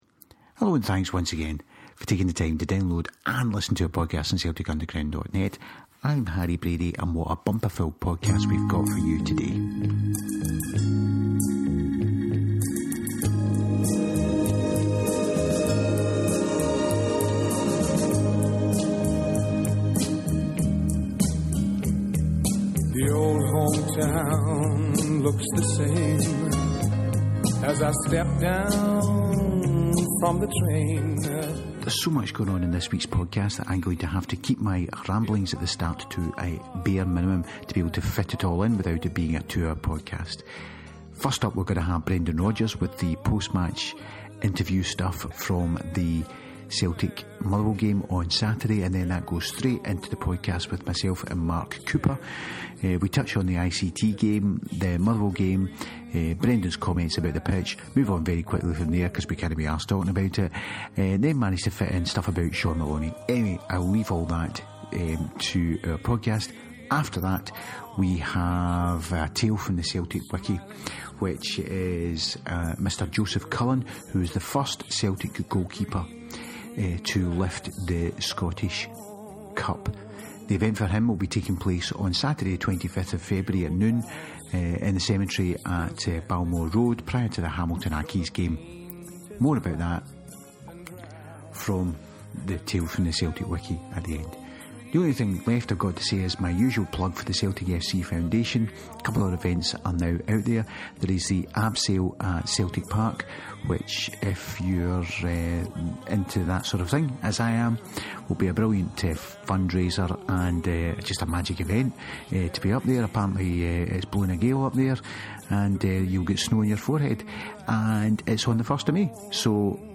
Prior to the bhoys chatting we have all the audio from the Brendan post Motherwell press conference and then we have the return of the Celtic Wiki with the tale of Jospeh Cullen who will be remembered at St Kentigerns Cemeterym Glasgow at noon on 25th February prior to the Hamilton Fixture.